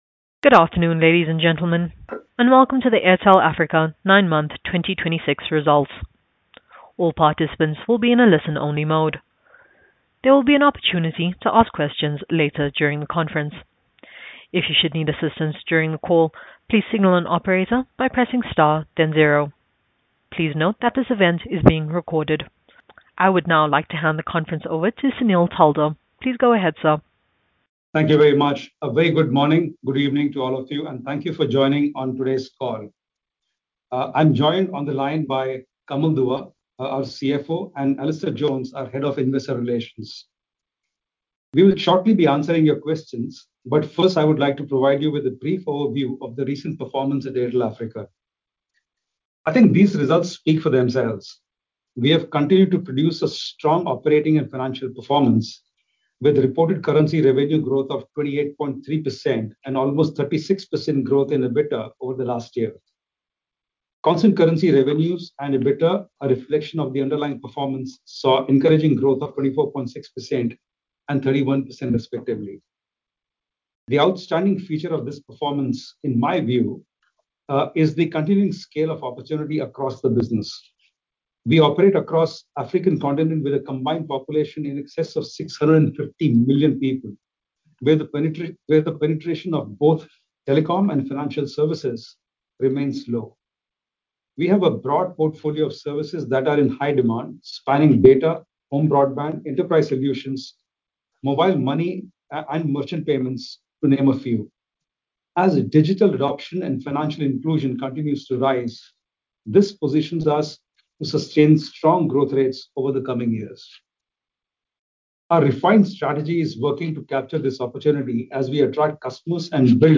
Conference call recording Q3 2026